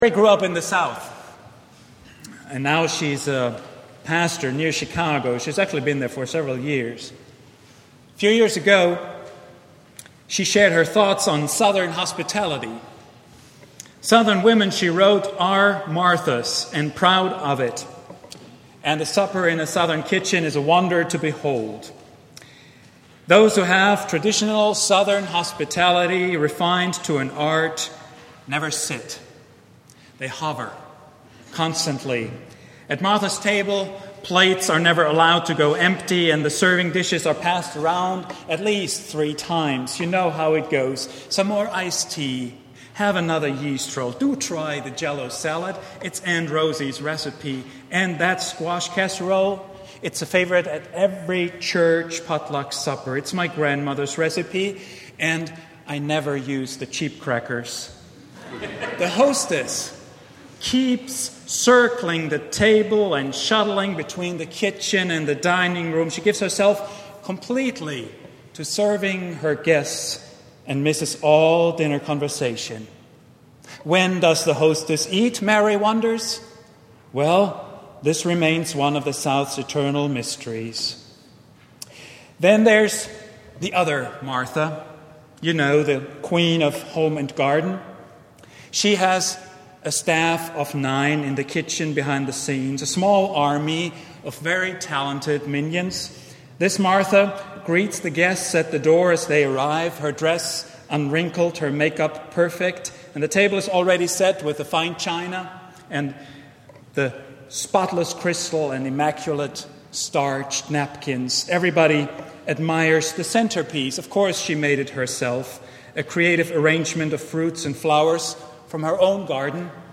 preached at Vine Street Christian Church on Sunday, July 18, 2010.